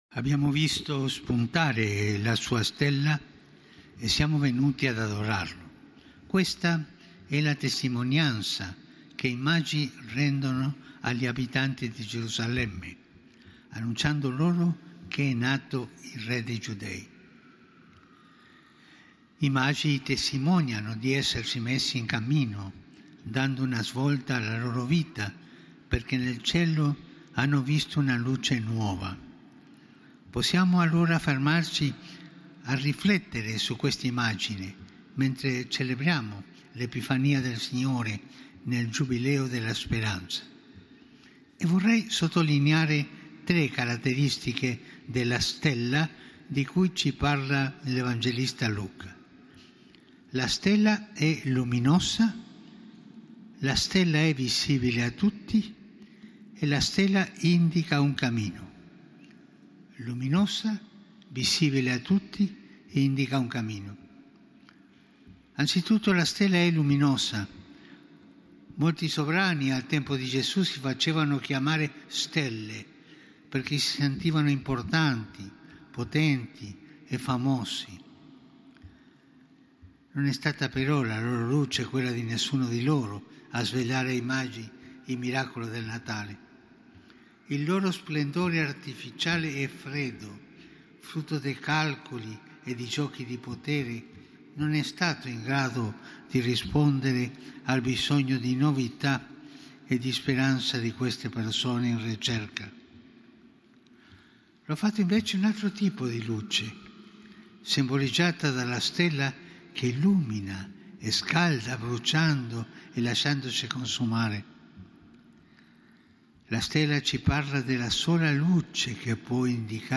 OMELIA DEL SANTO PADRE FRANCESCO
Basilica di San PietroLunedì, 6 gennaio 2025